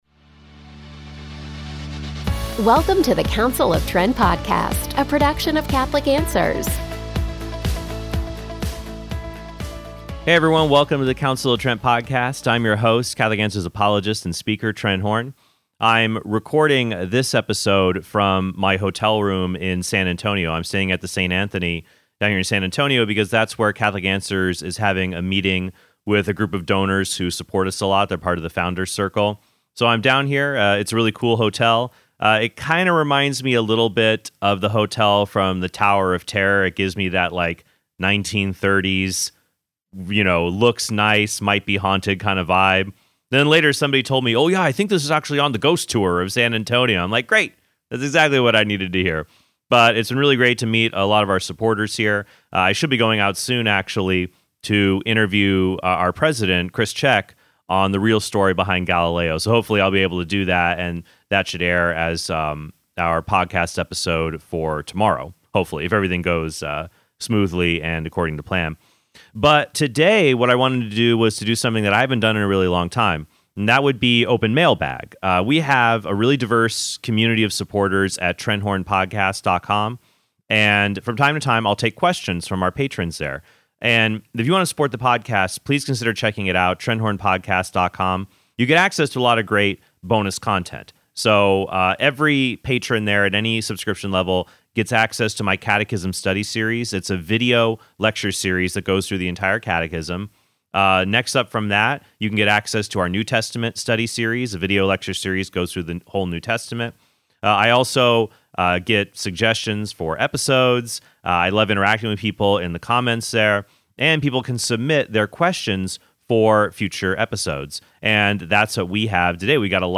In this “open mailbag episode”